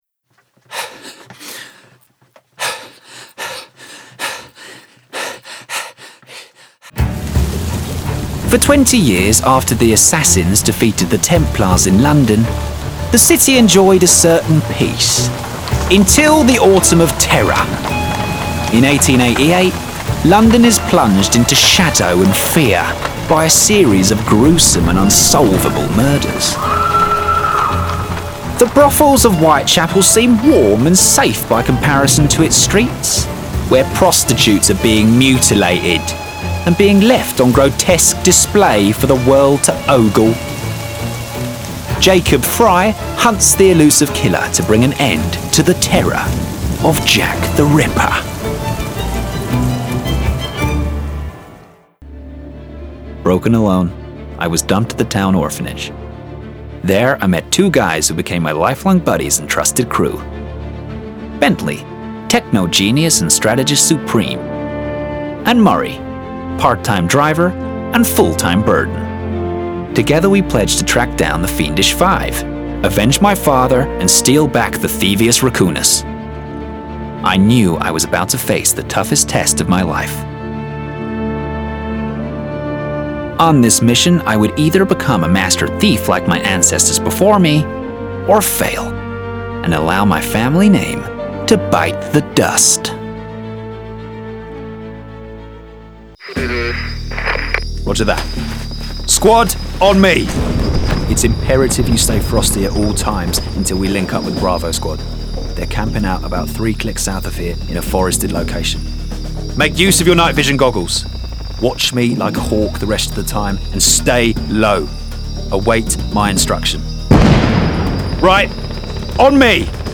Male
English (British)
Yng Adult (18-29), Adult (30-50)
A distinctive voice which is rich, smooth and rambunctious.
A warm and versatile voice.
Video Games
Updated Video Game Reel
0430Video_Game_Reel.mp3